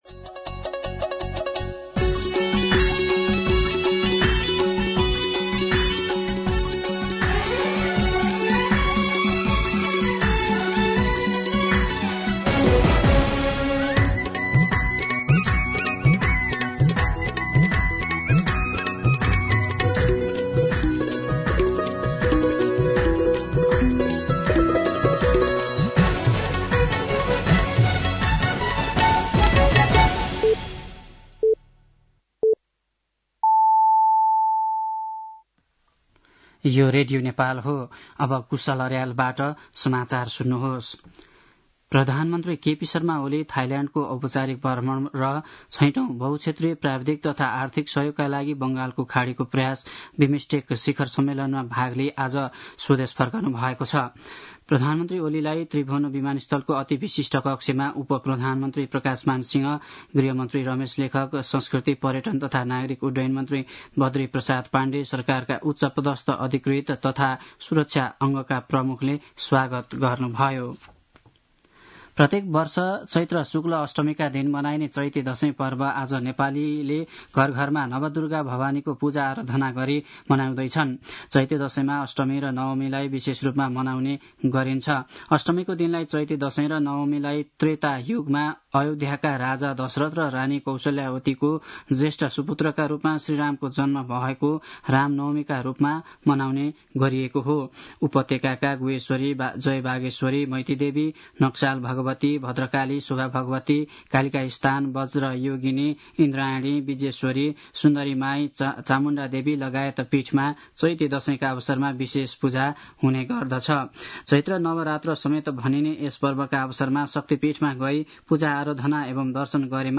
दिउँसो १ बजेको नेपाली समाचार : २३ चैत , २०८१